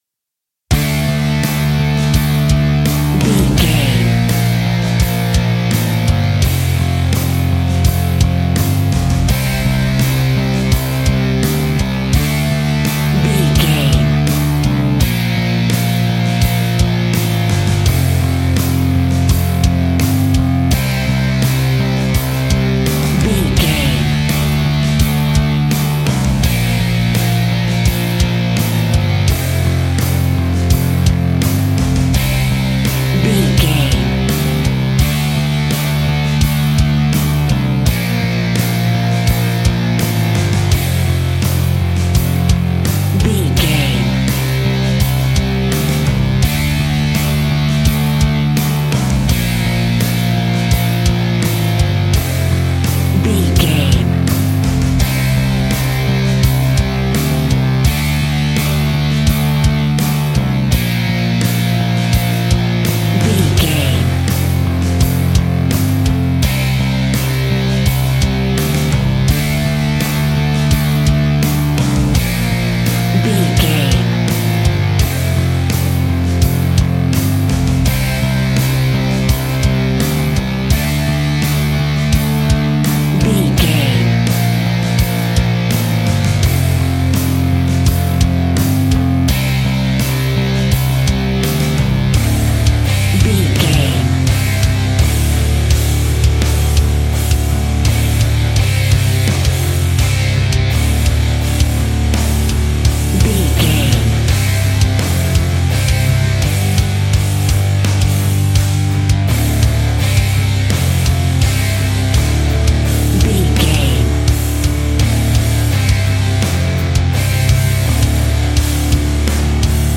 Fast paced
Aeolian/Minor
D
hard rock
heavy metal
rock instrumentals
Heavy Metal Guitars
Metal Drums
Heavy Bass Guitars